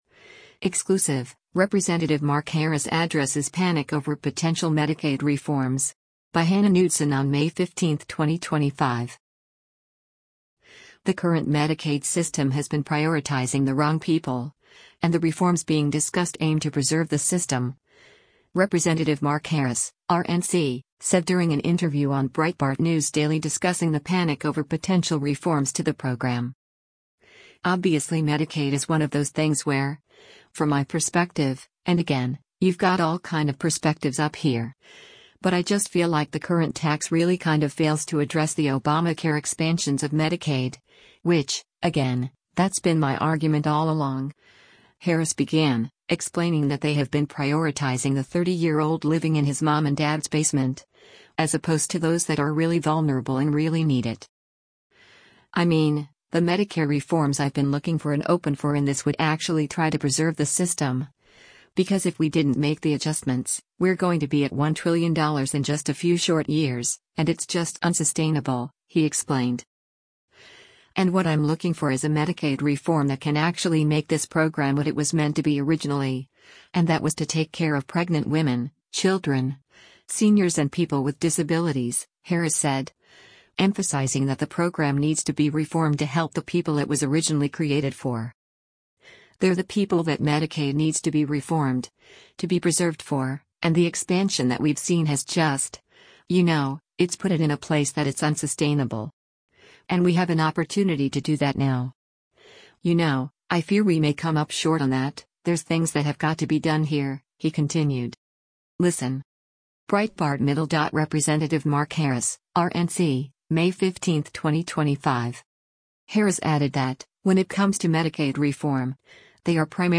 The current Medicaid system has been prioritizing the wrong people, and the reforms being discussed aim to preserve the system, Rep. Mark Harris (R-NC) said during an interview on Breitbart News Daily discussing the panic over potential reforms to the program.